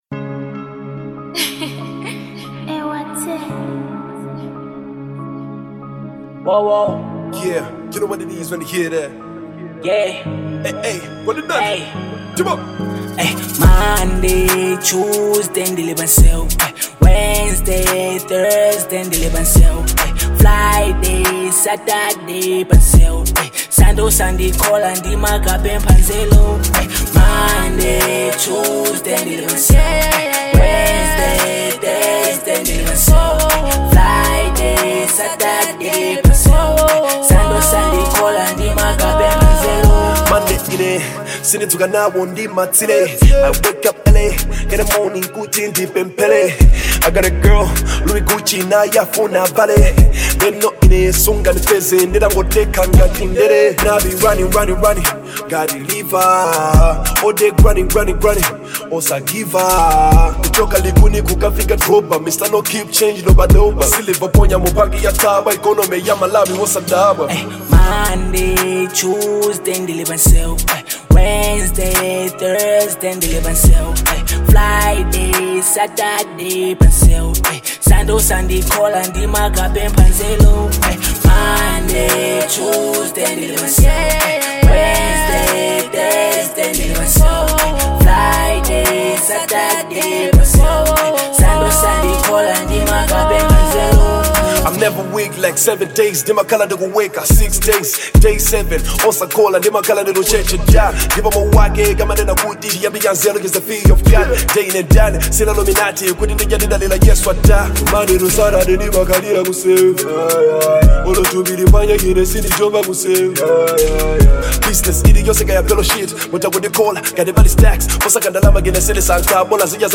Genre : Hiphop/Trap